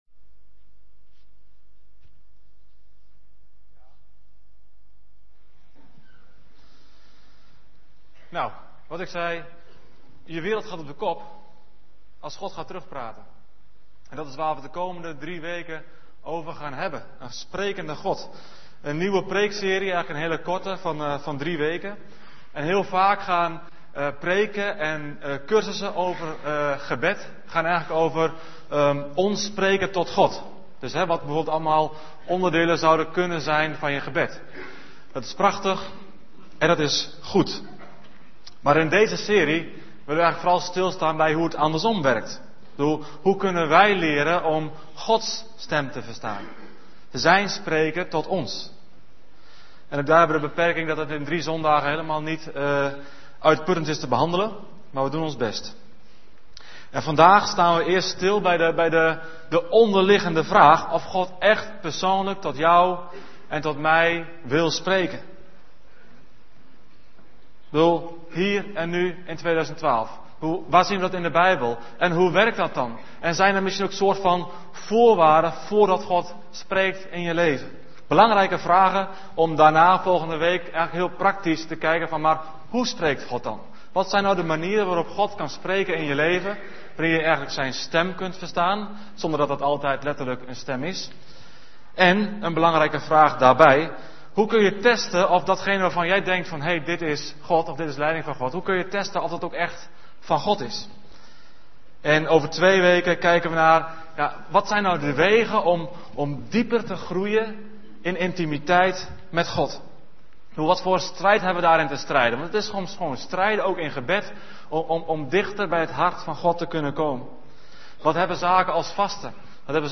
Om ook de preken van de zondagse diensten te kunnen beluisteren op welk moment en waar je maar wilt, worden de preken ook als audio-uitzending gedeeld.